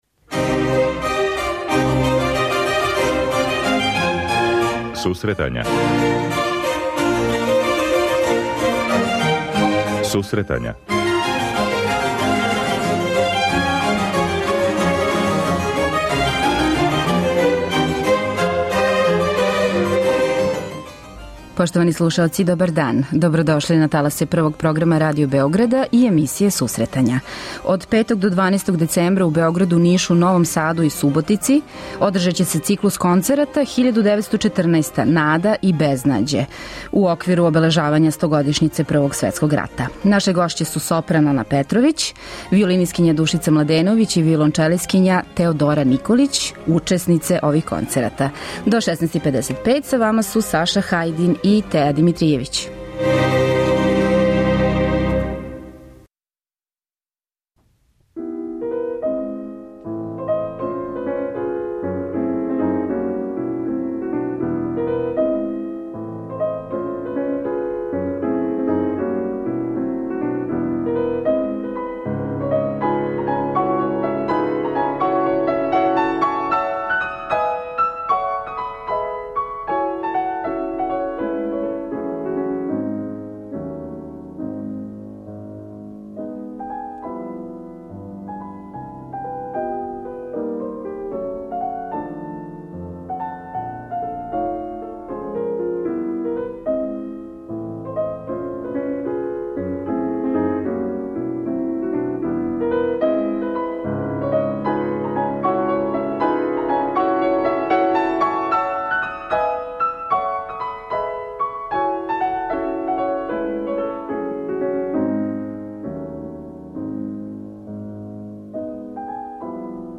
преузми : 26.25 MB Сусретања Autor: Музичка редакција Емисија за оне који воле уметничку музику.